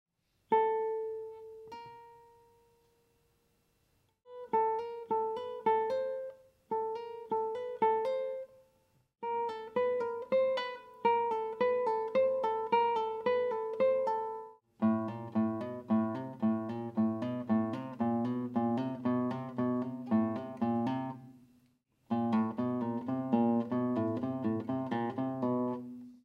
15. ligados.m4v